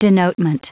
Prononciation du mot denotement en anglais (fichier audio)
Prononciation du mot : denotement